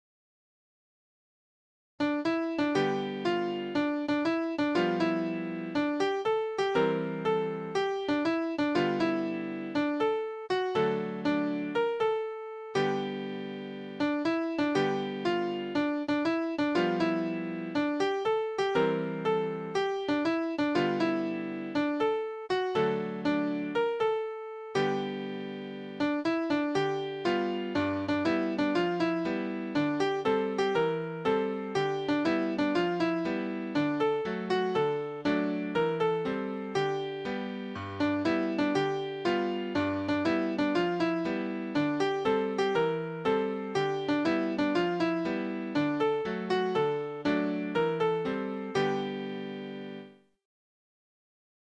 konvertiert aus capella 10 und weiter mit NCH Switch